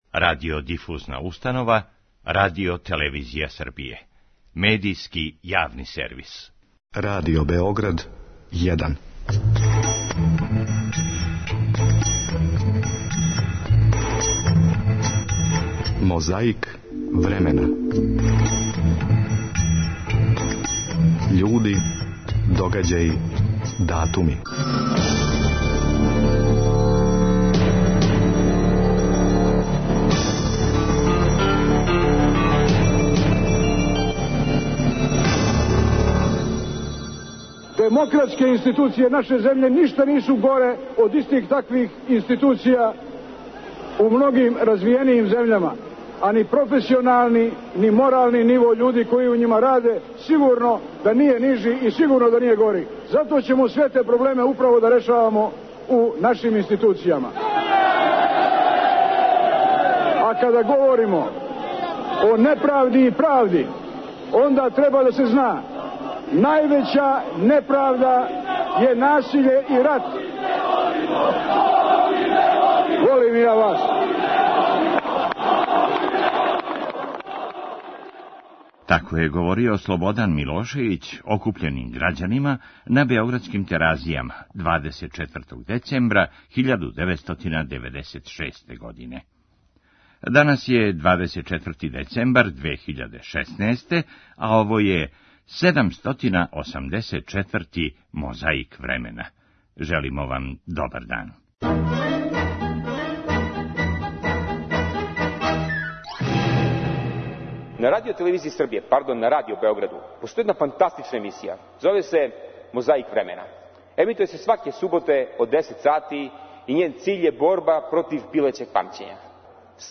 Овонедељну емисију почињемо говором Слободана Милошевића окупљеним грађанима на Теразијама у Београду, на митингу 'За Србију' 24. децембра 1996. године.
После избора на ред је дошла конференција за новинаре 29. децембра 2003. године.